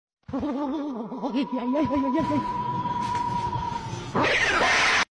Warning: Loud Nosies